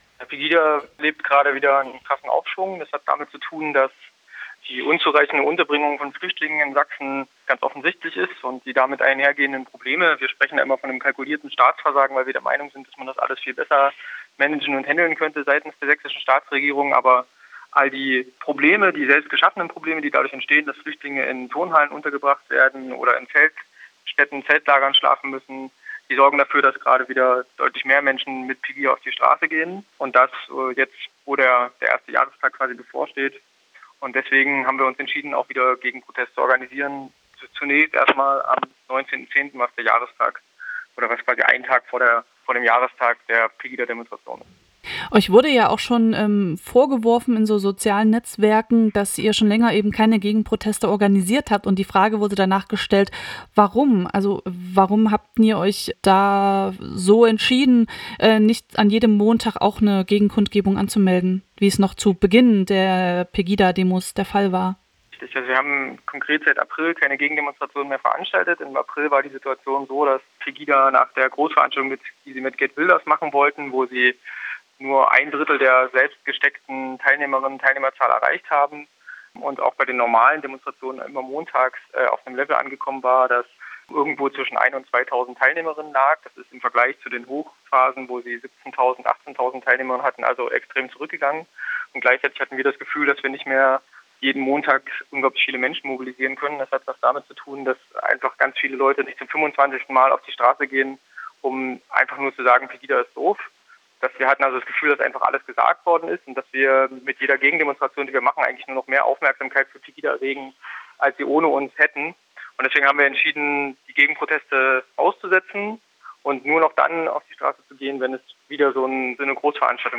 Hintergründe zur Antifa-Demo in Wurzen: Interview mit Leipziger Bündnis gegen Rechts.(Beitrag von Querfunk KA)